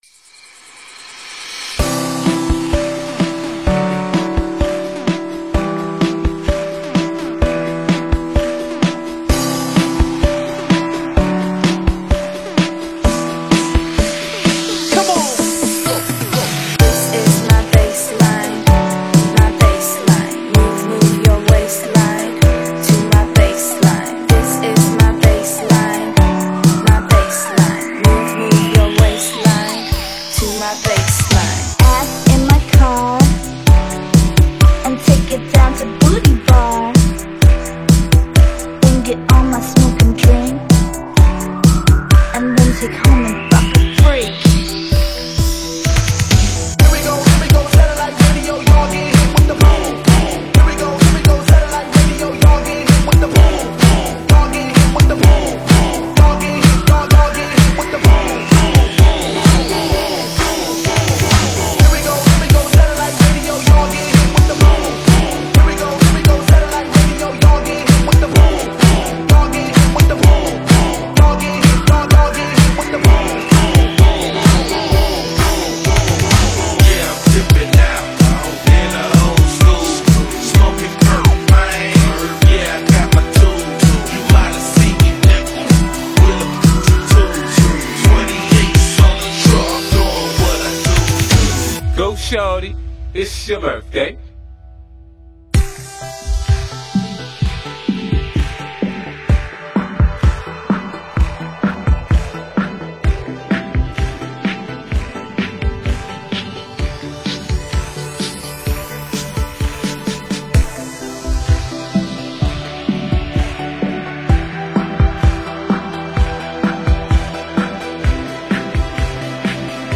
本铃声大小为2150.4KB，总时长353秒，属于DJ分类。